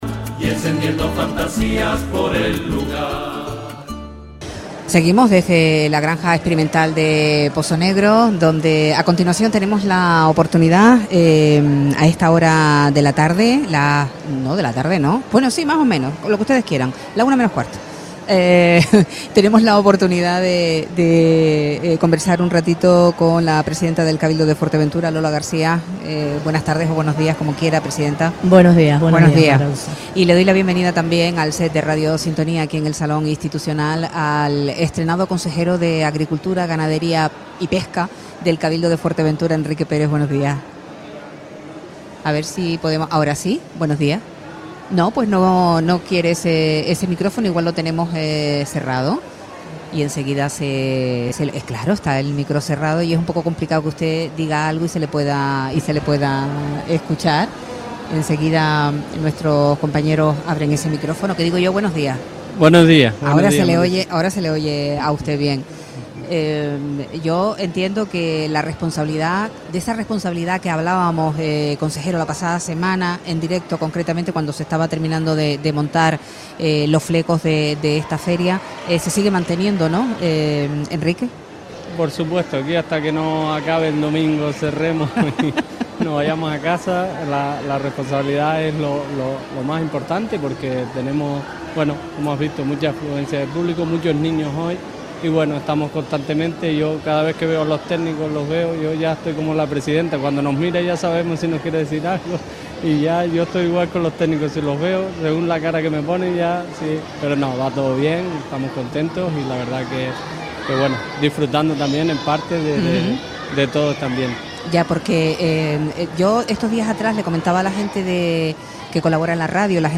Entrevista a Lola García, presidenta del Cabildo de Fuerteventura y Enrique Pérez, consejero del sector primario - Radio Sintonía